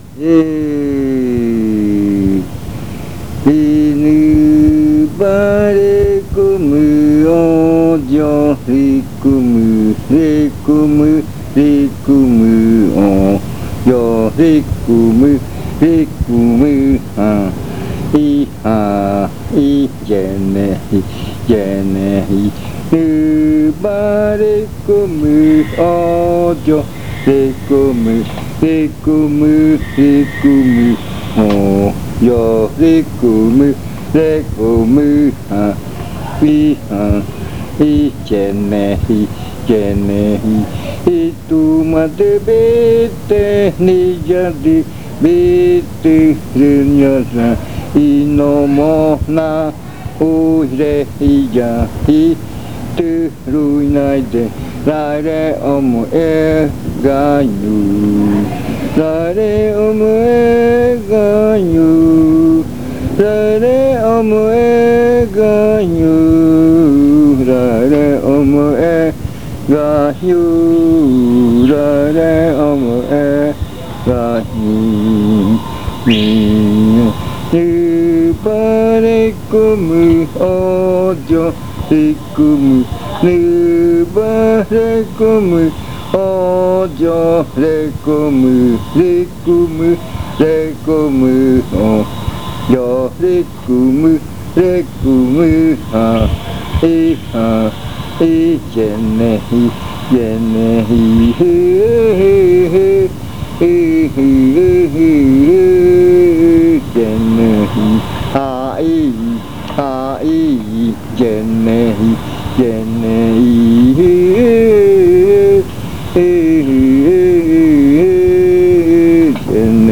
Leticia, Amazonas
Canción con adivinanza.
Chant with riddle.